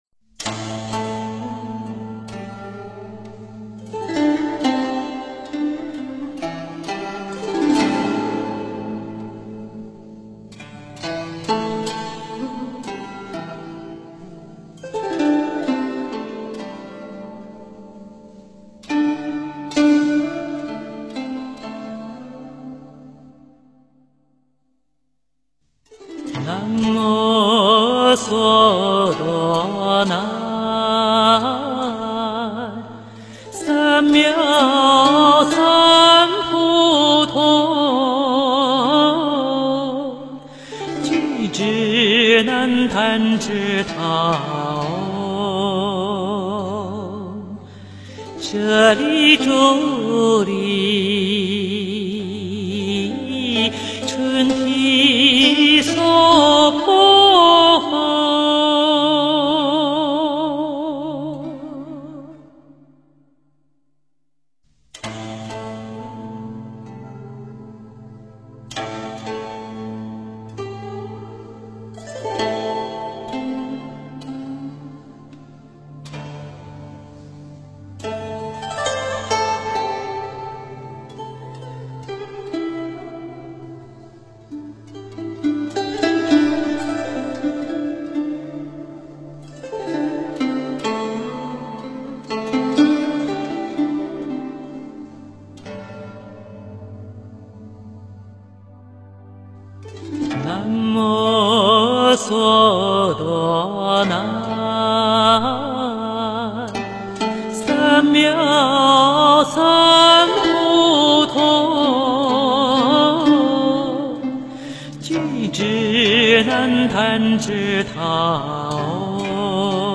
佛音 诵经 佛教音乐 返回列表 上一篇： 大悲咒(古筝版